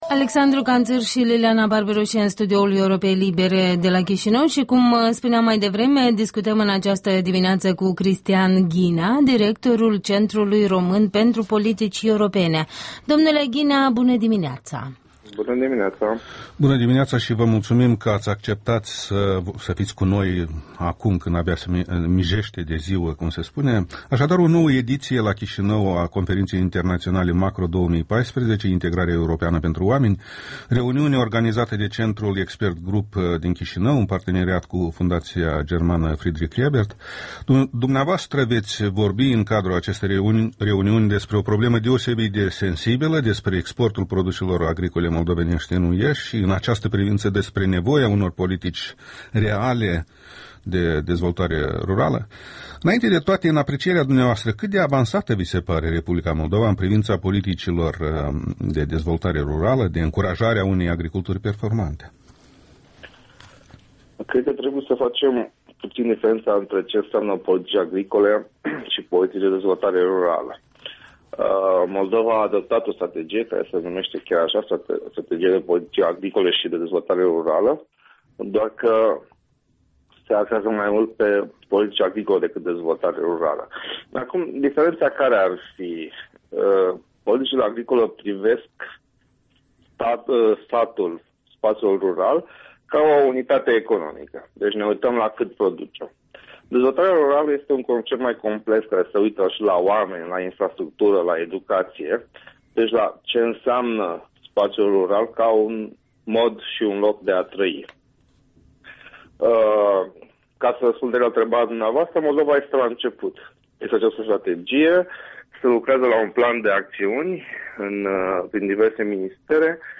Interviul dimineții cu directorul Centrului Român pentru Politici Europene despre prioritățile în dezvoltarea agriculturii moldovenești.
Interviul dimineții: cu Cristian Ghinea, director al Centrului Român pentru Politici Europene